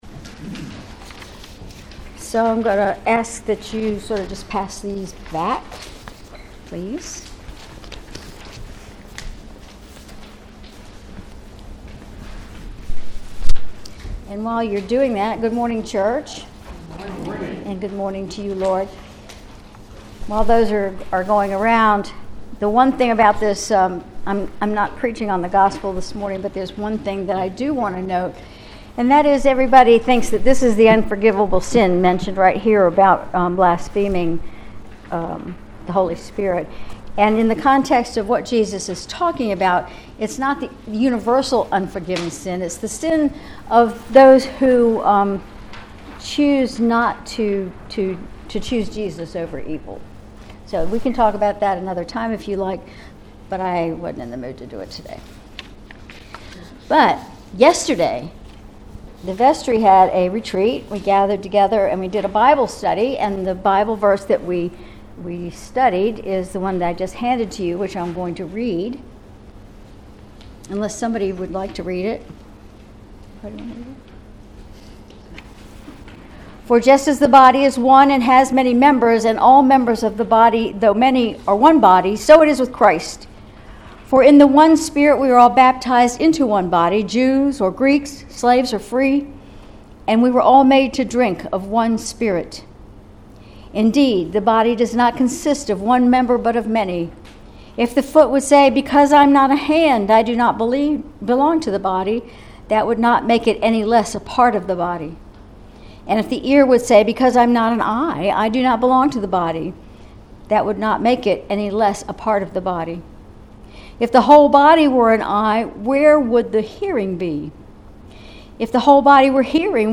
Sermon June 9, 2024
Sermon_June_9_2024.mp3